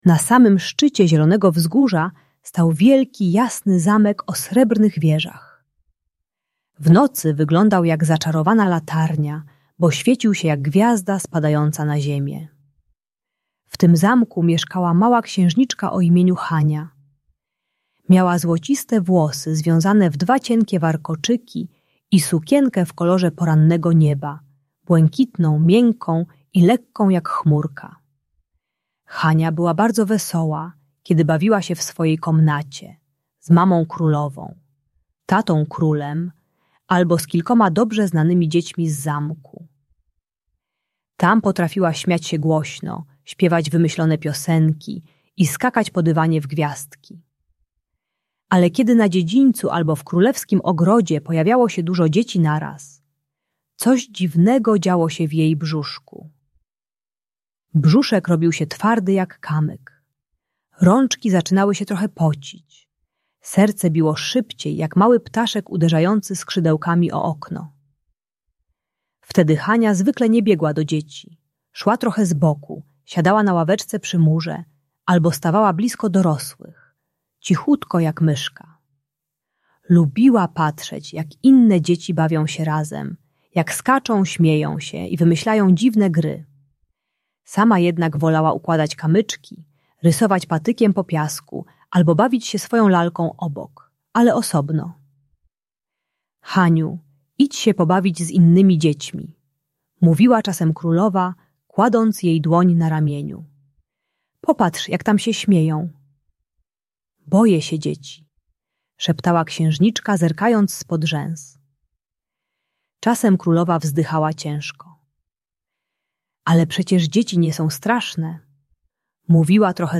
Audiobajka o nieśmiałości i lęku przed rówieśnikami.